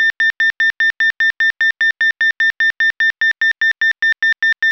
Avisador mixto
Sonido intermitente
intermitente_rapido
intermitente_rapido.wav